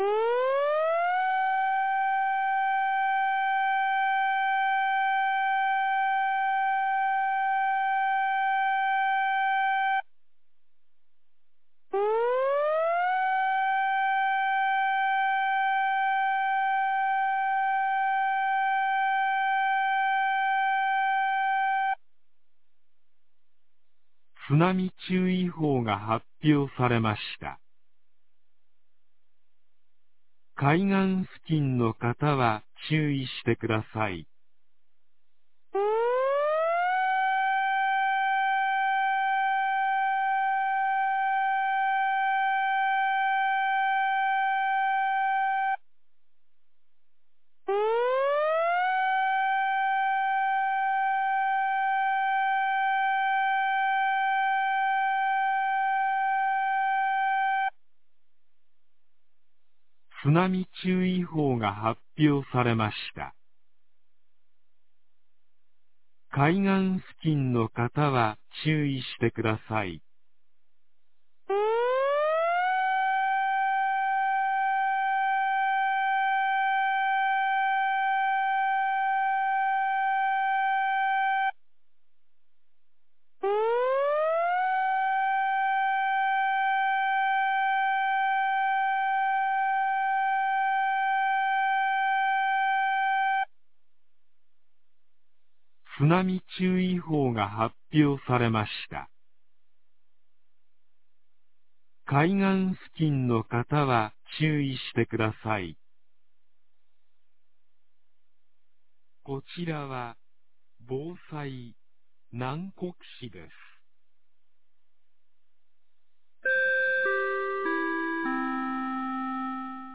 南国市放送内容
2025年01月13日 21時31分に、南国市より放送がありました。